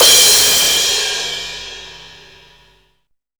CRASHDIST2-R.wav